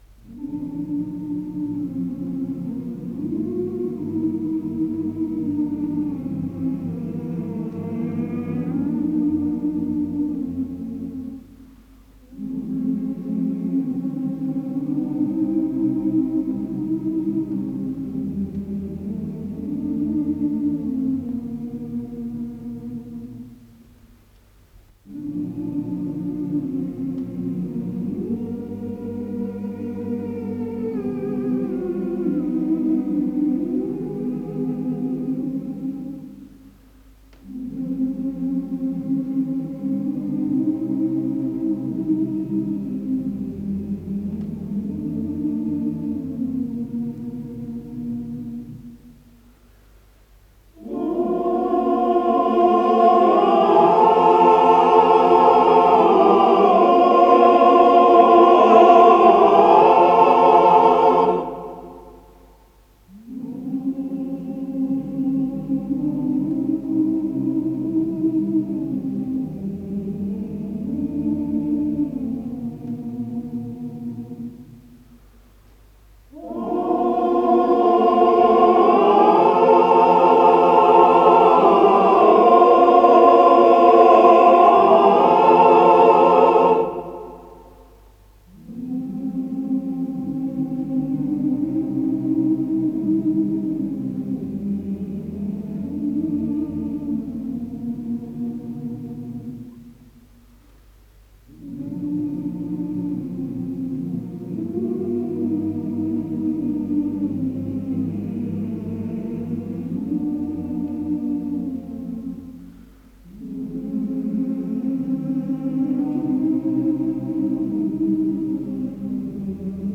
с профессиональной магнитной ленты
ПодзаголовокСоч. 30 №3, вокализ, оригинал для фортепиано
ИсполнителиАнсамбль песни и пляски Московского военного округа
Скорость ленты38 см/с
ВариантДубль моно